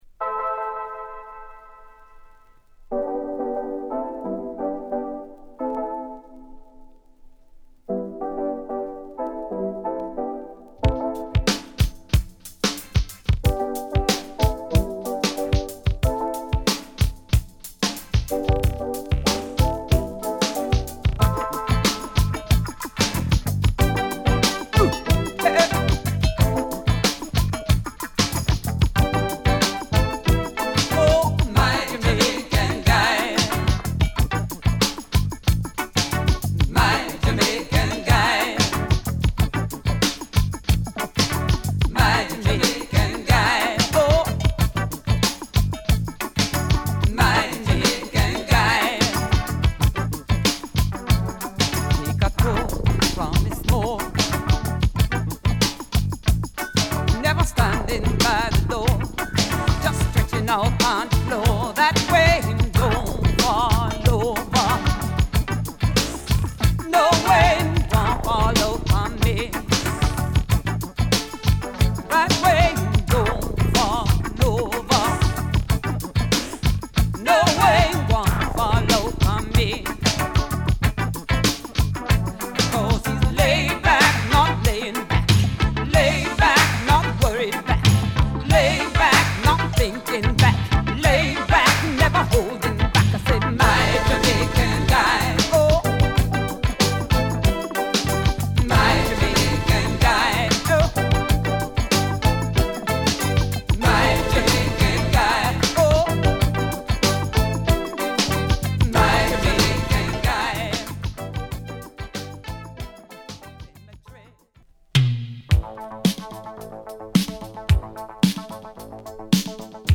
ディスコ/R&Bを基調としながらも、オリジナルな楽曲を披露！